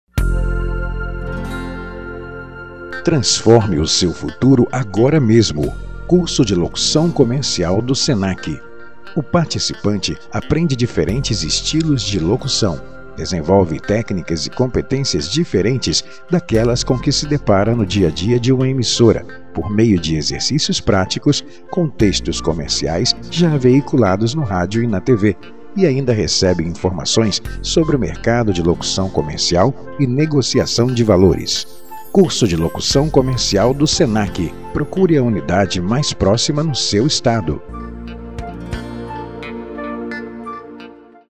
Sprechprobe: Werbung (Muttersprache):
voice-over